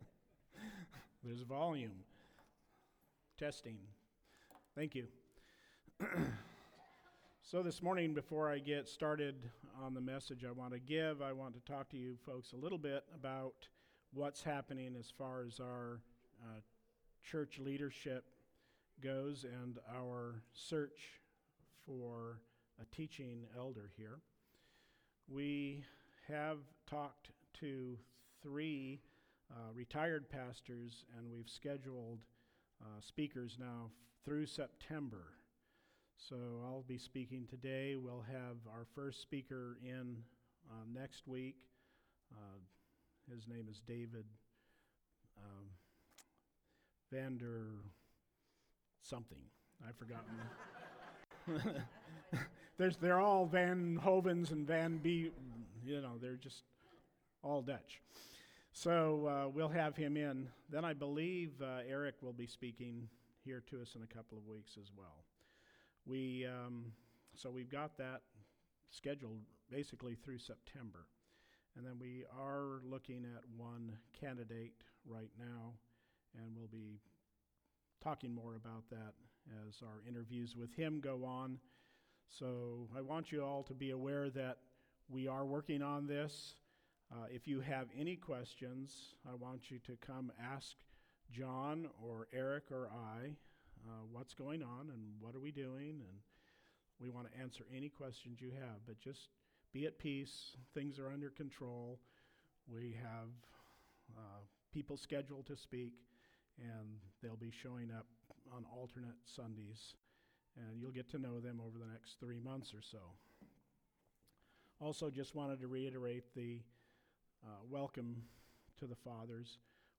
Sermons Archive - Page 3 of 18 - New Life Fellowship